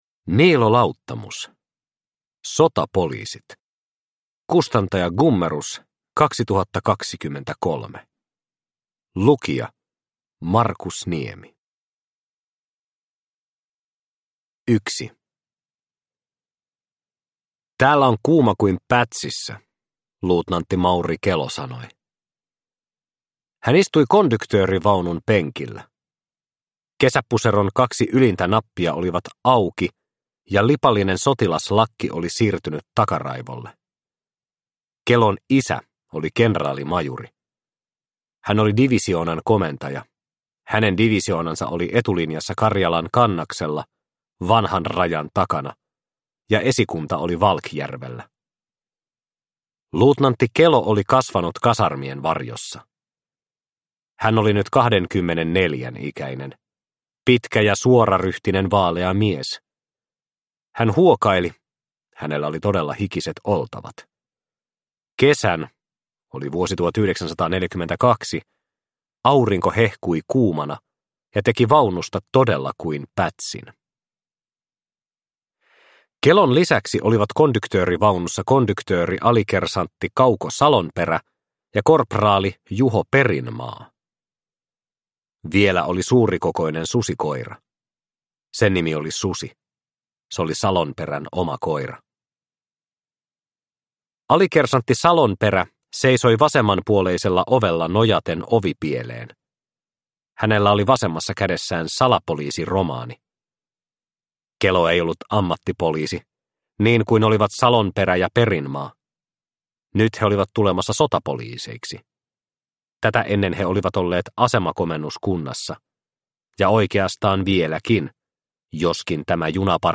Sotapoliisit – Ljudbok – Laddas ner